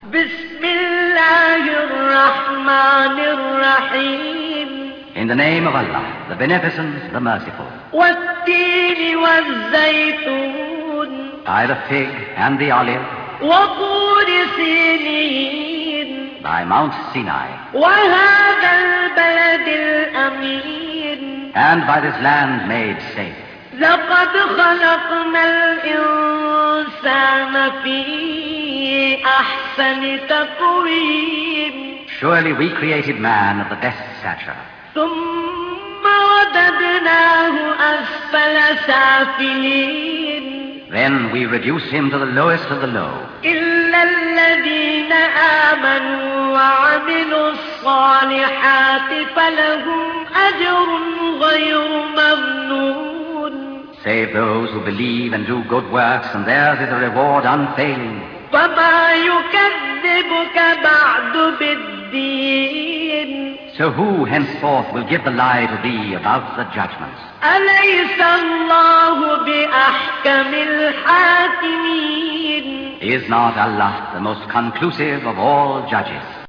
· Recitation of Quran